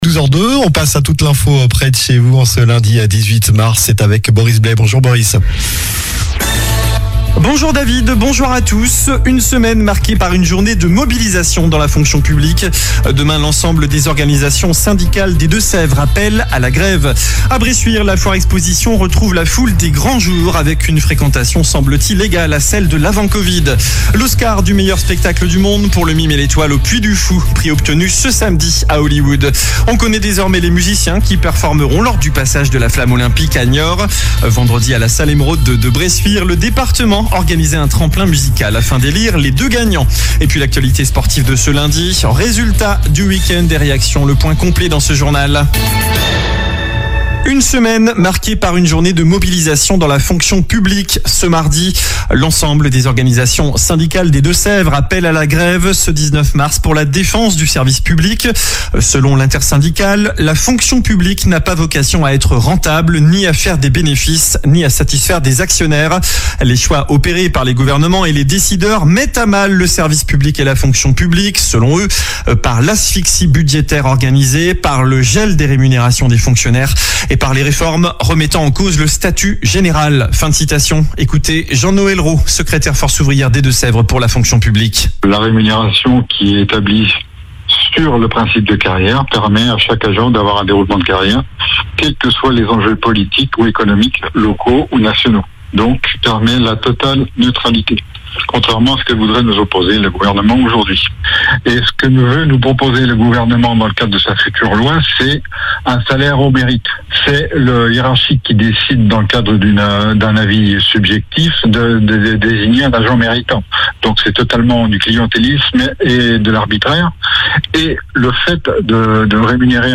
Journal du lundi 18 mars (midi)